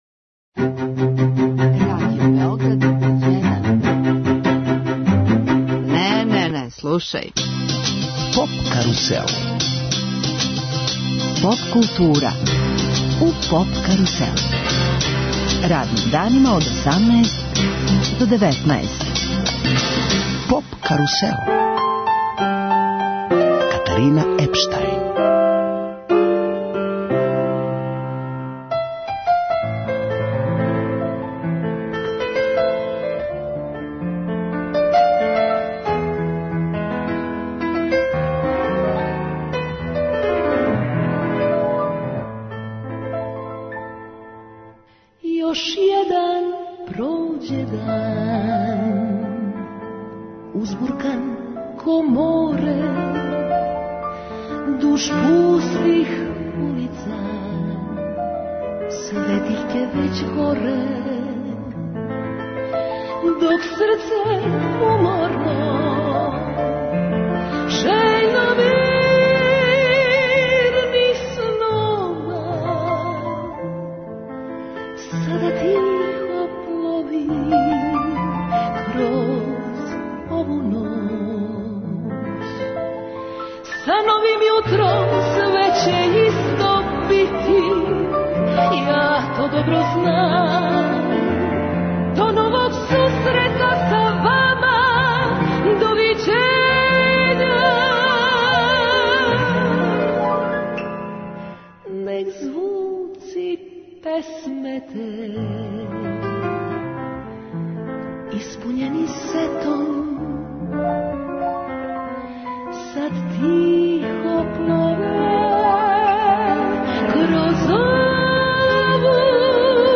мецосопран
певачица која негује изворну музичку традицију.